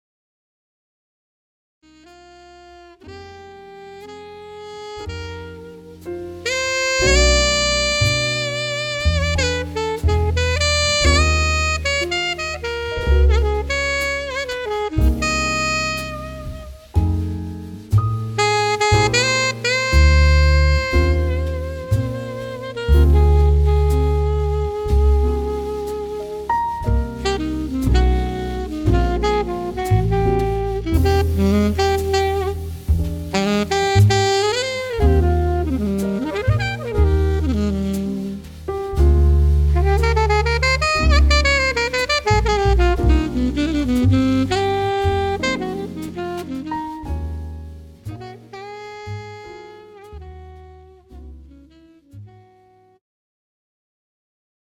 The Best In British Jazz